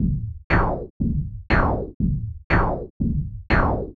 Index of /saauve2/AJ1_Wav_Lps/Drum Loops/120 Bpm